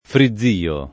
frizzio [ fri zz& o ] s. m.